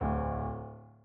sfx_猪头走路2.ogg